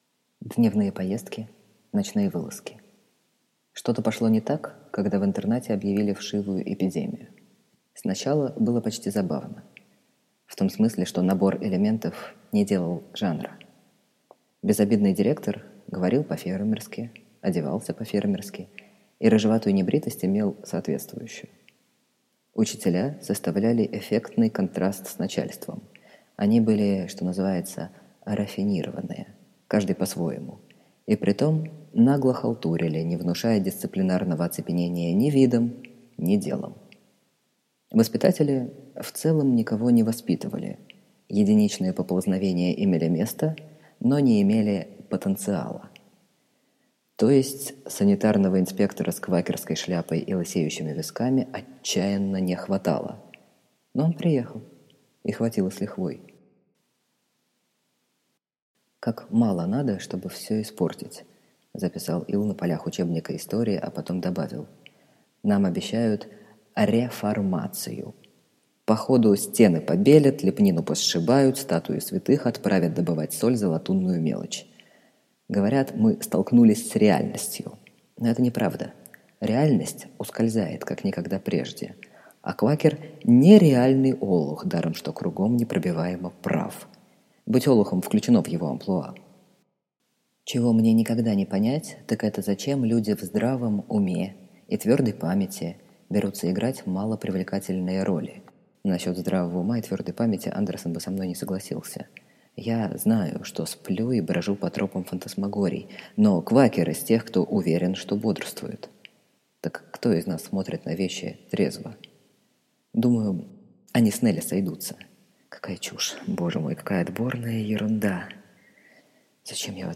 Аудиокнига Дневные поездки, ночные вылазки. I. Нулевой километр. II. Нерукотворные лестницы | Библиотека аудиокниг